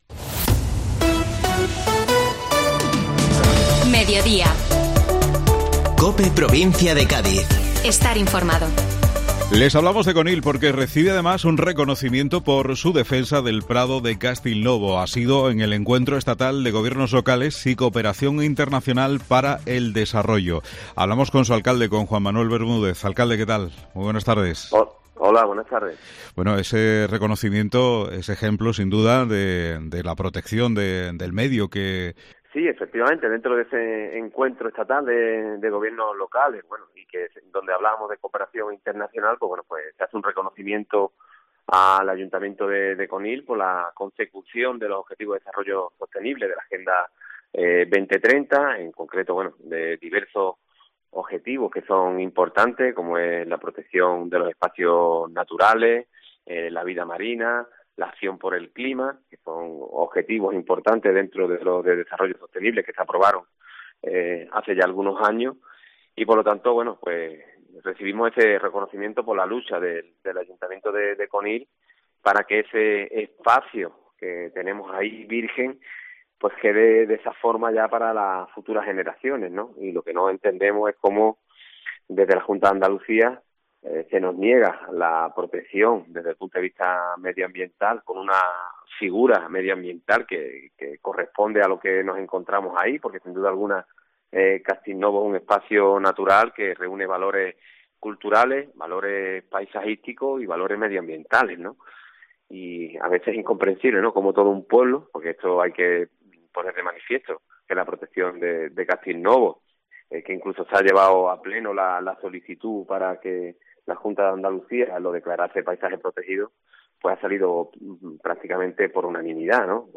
El Alcalde de Conil habla del reconocimiento recibido por la protección del prado de Castilnovo y reclama a la Junta la protección medioambiental, además muestra su enfado por las obras que ha comenzado Costas en la zona de albero pegada al río sin previo aviso al Ayuntamiento.